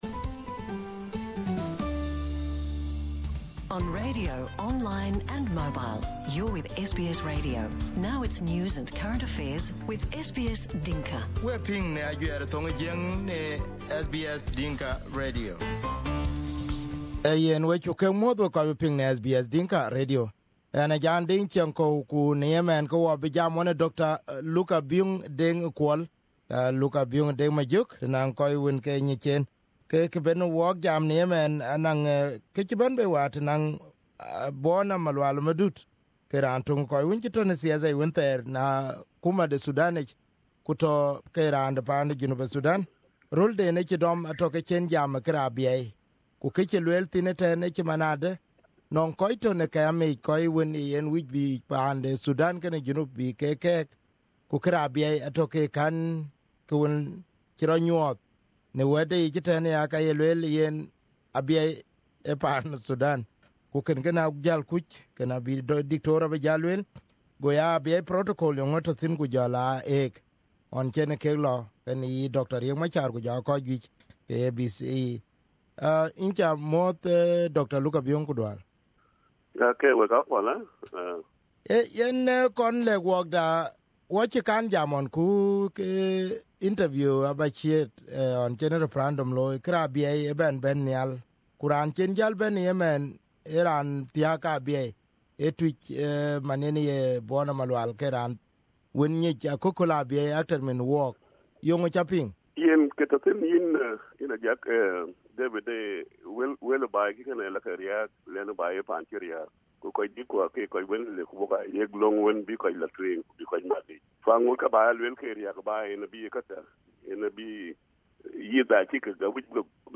Here the interview with Dr. Luka Share